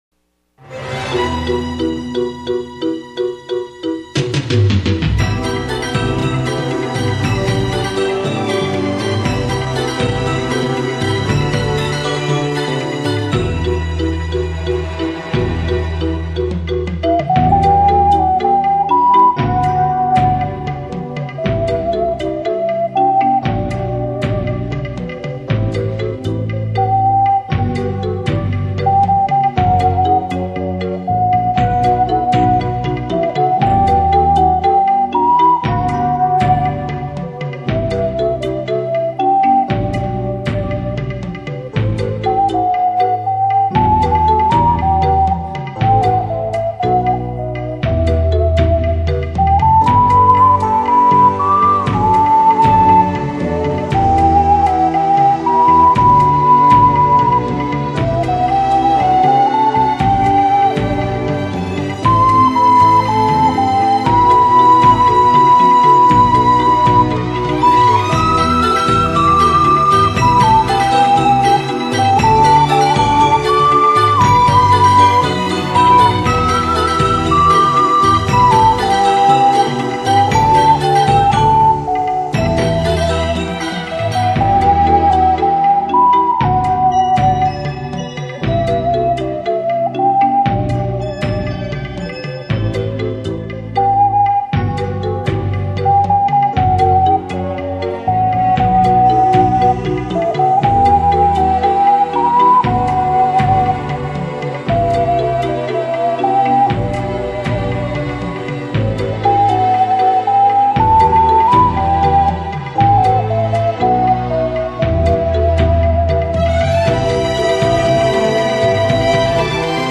오카리나 연주곡 베스트 모음